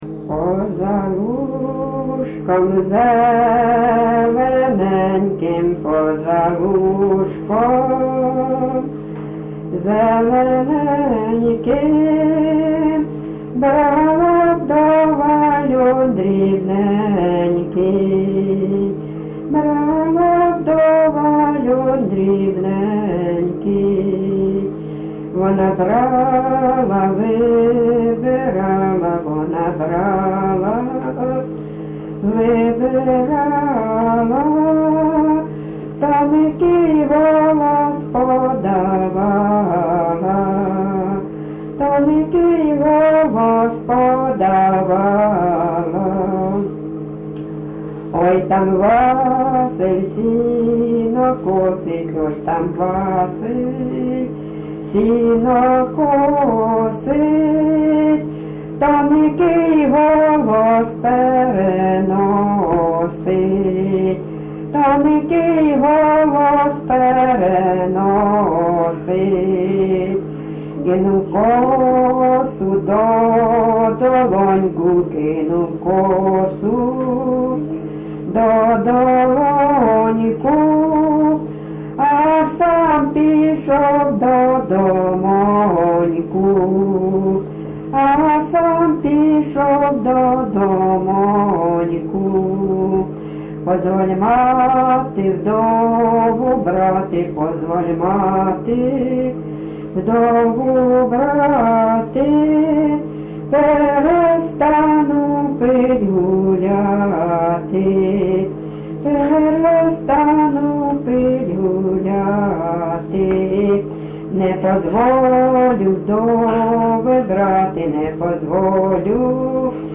ЖанрПісні з особистого та родинного життя
Місце записус. Остапівка, Миргородський район, Полтавська обл., Україна, Полтавщина